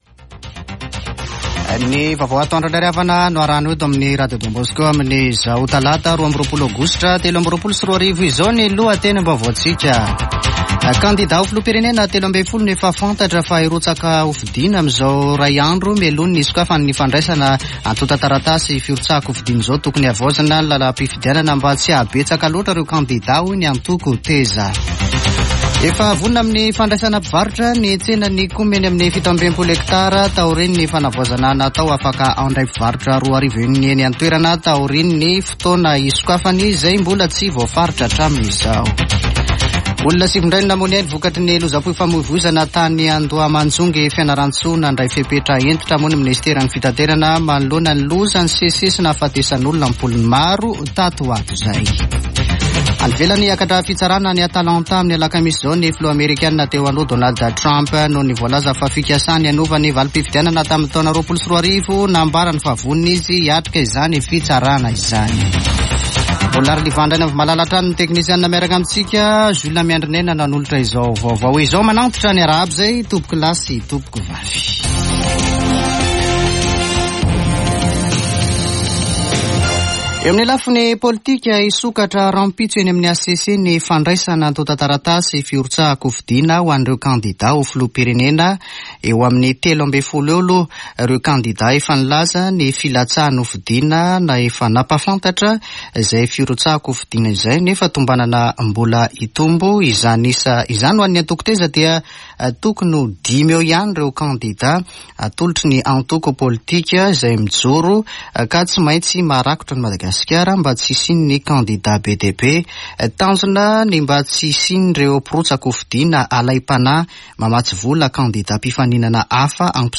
[Vaovao antoandro] Talata 22 aogositra 2023